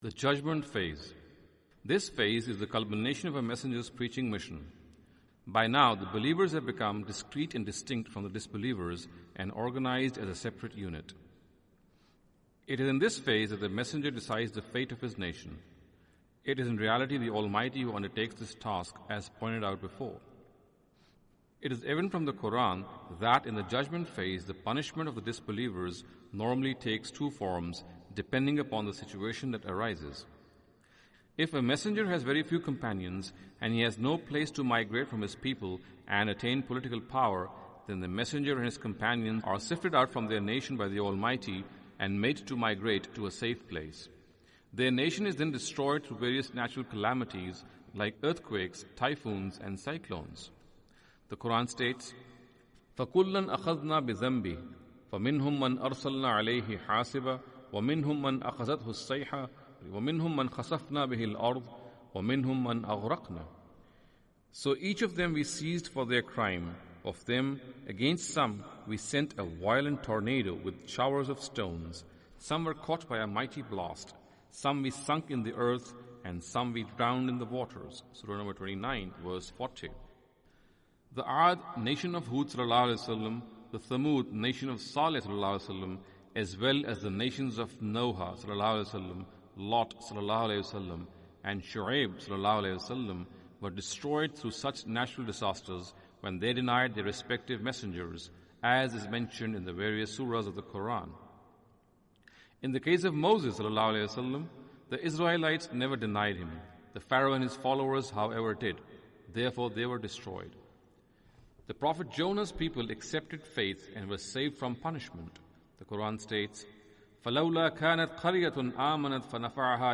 Audio book of English translation of Javed Ahmad Ghamidi's book "Playing God".